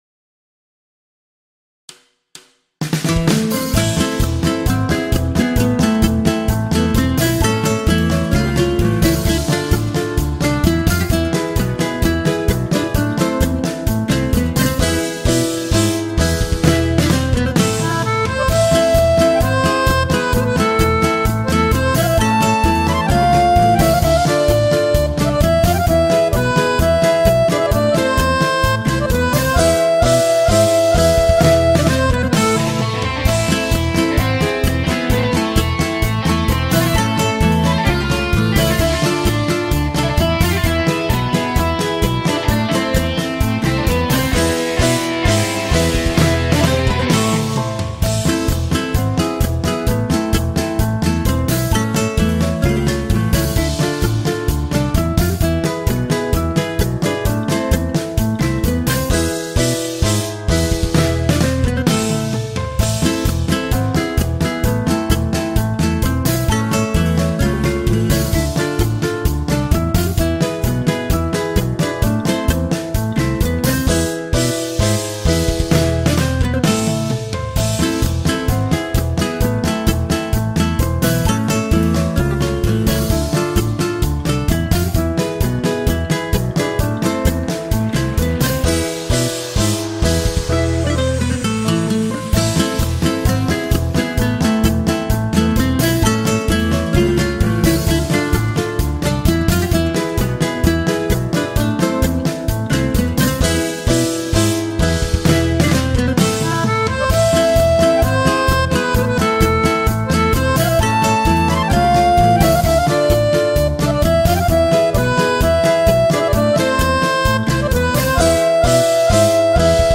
Playback kan lastes ned
hevenu-shalom-playback.mp3